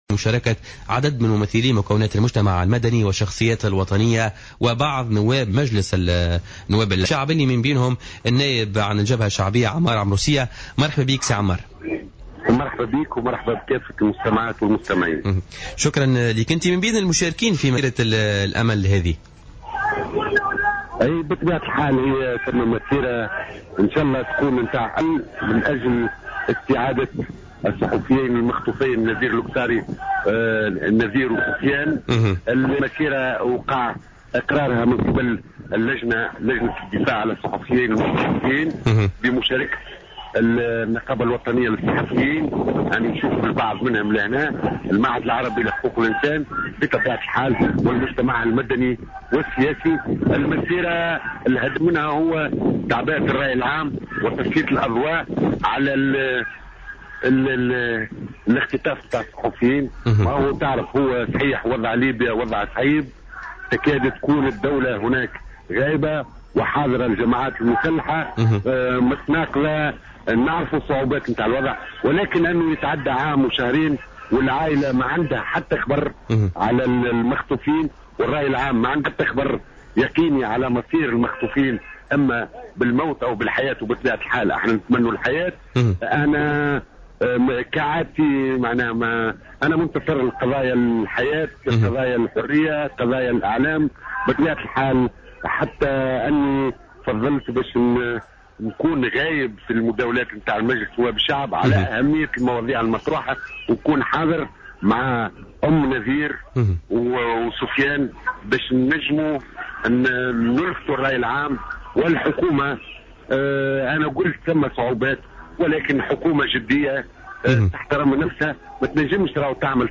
Le député du Front populaire à l’assemblée des représentants du peuple Ammar Amroussia, était l’invité de Jawhara FM dans le cadre de Politica du mercredi 21 octobre 2015.